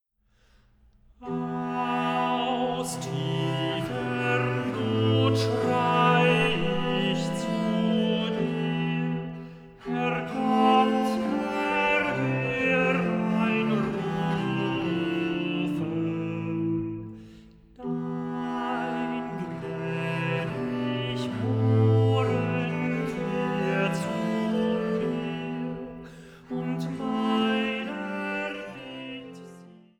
Sopran
Tenor
Blockflöte
Viola da gamba
Orgel